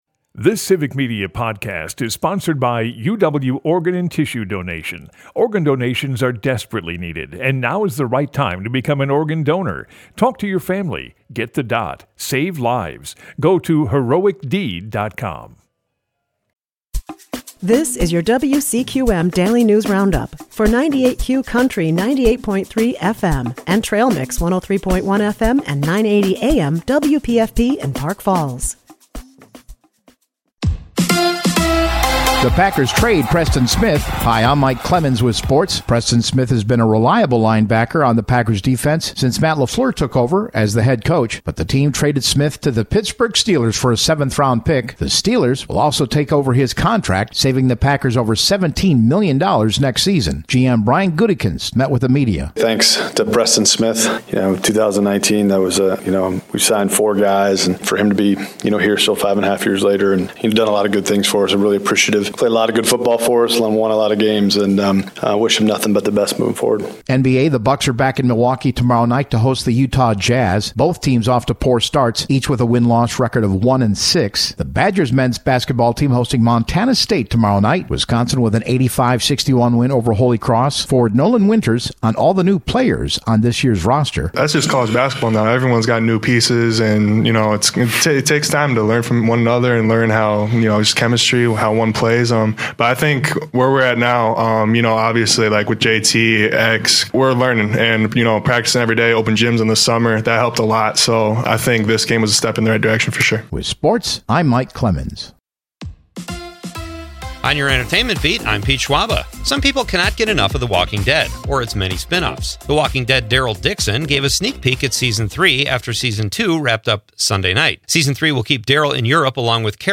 wcqm news